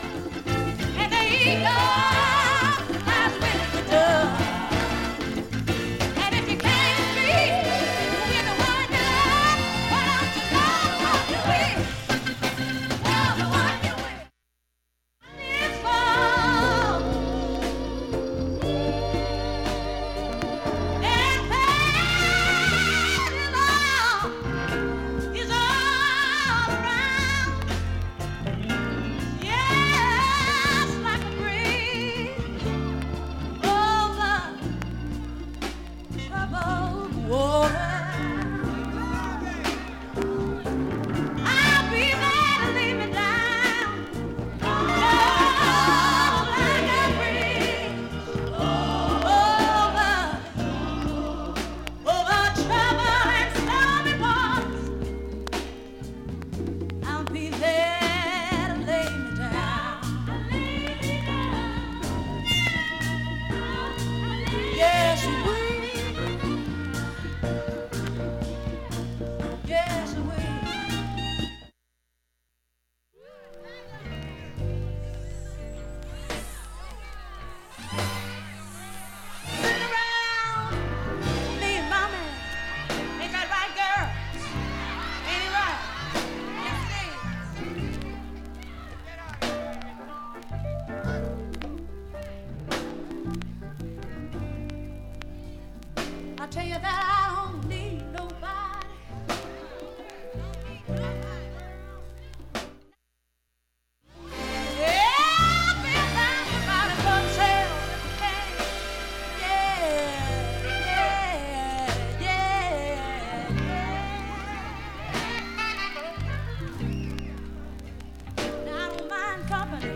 チリも無くいい音質です。
1,A-2後半にかすかなプツが5回出ます。
５回までのかすかなプツが３箇所
３回までのかすかなプツが７箇所
単発のかすかなプツが７箇所
ソウル史上最高傑作ライブ